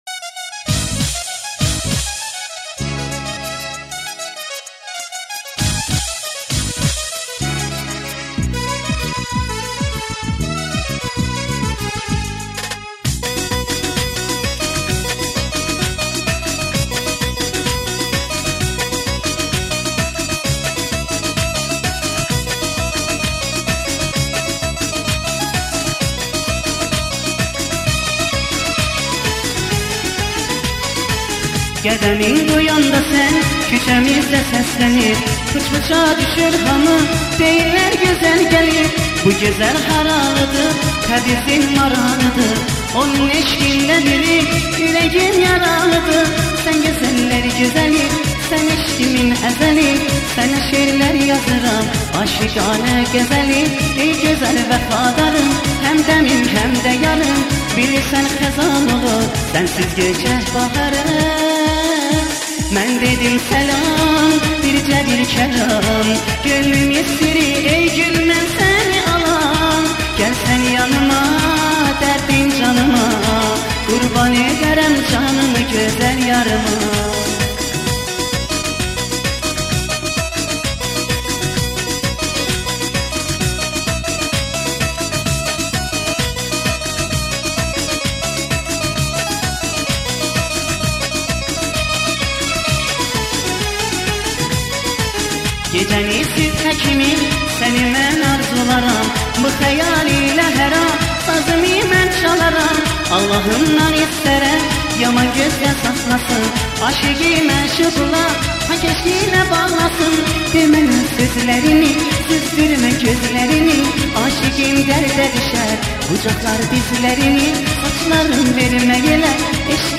سبک او بیشتر آرام و ملودیک است….